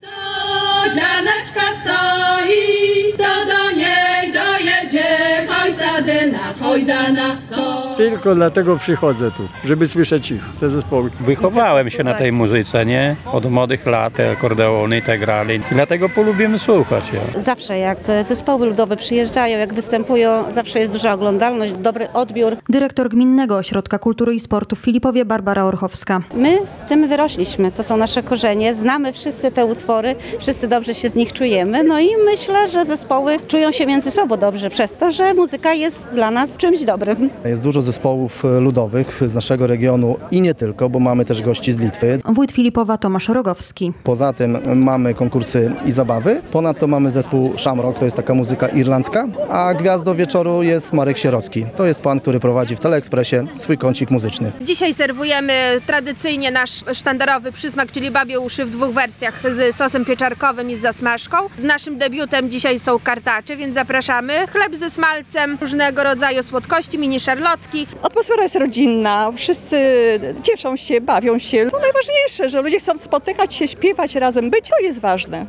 Folklor, biesiadowanie i dyskoteka - w Filipowie trwa gminny festyn